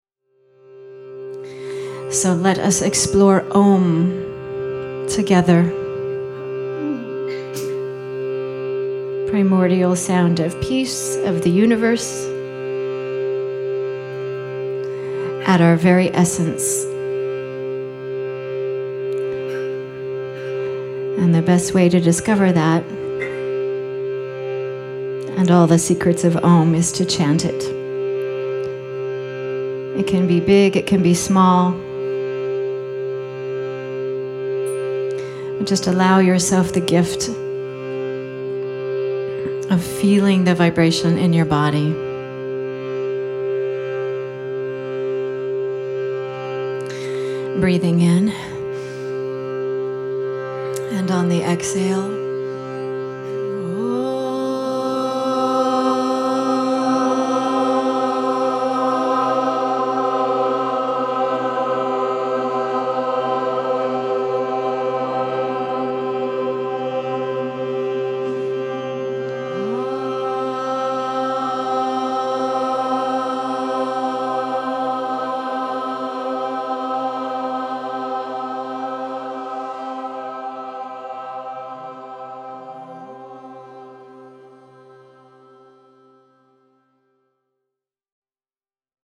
Experience the eternal peace, endless joy, and unwavering clarity that awakens all the way to the cellular level through these teachings from 3 different weekend retreats in 2015. 10 tracks with a total running time: 7 hours, 15 mins.
Also included are three Q&A sessions and two sacred chants to help you absorb the transmission of healing energy and messages at an even deeper level.
Seattle Immersion March 22, 2015
timeless sacred mantra